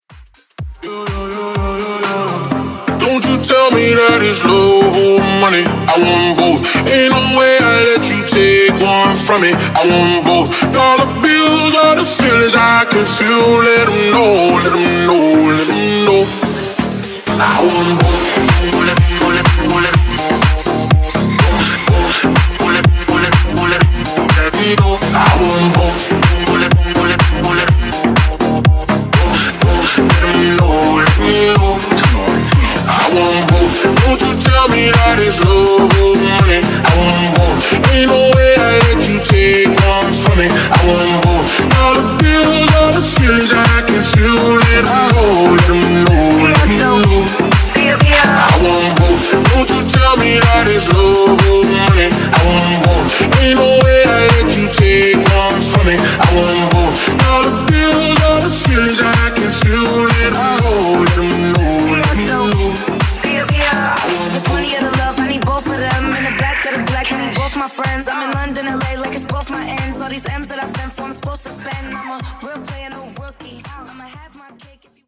Genre: MOOMBAHTON Version: Clean BPM: 108 Time